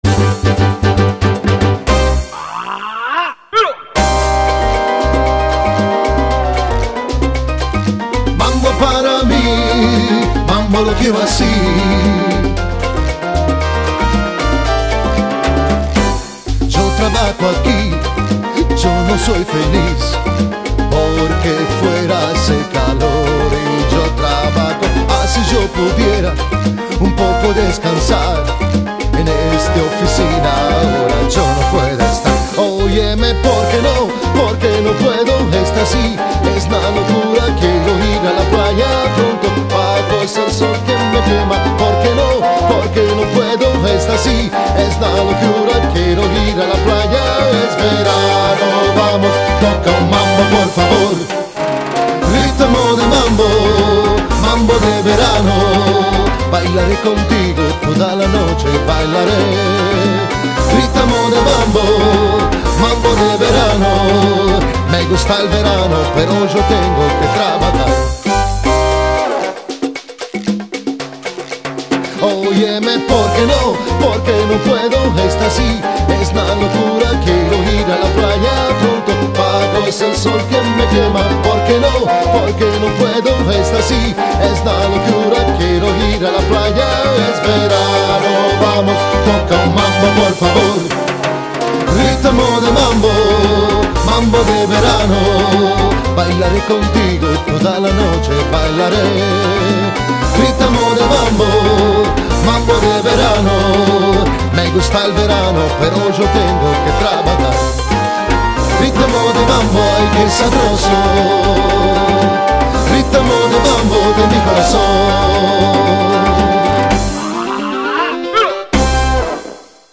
Party music to represent good times!